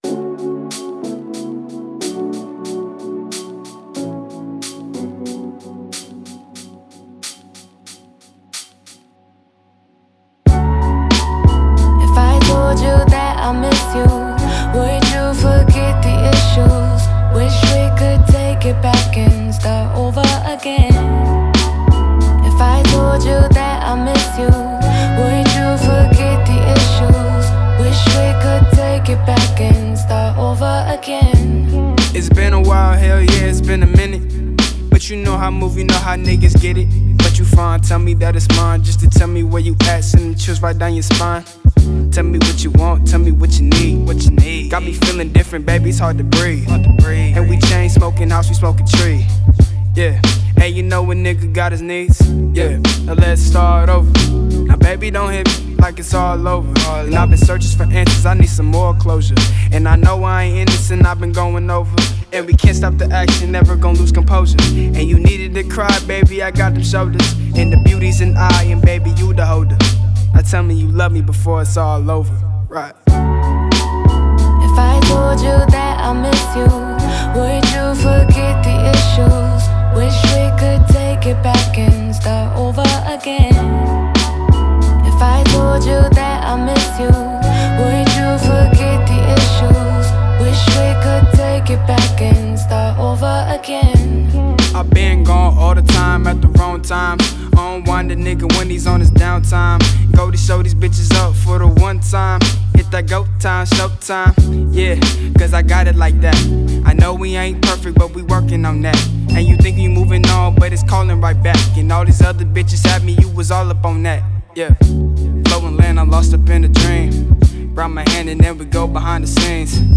Description : HIp Hop/R&B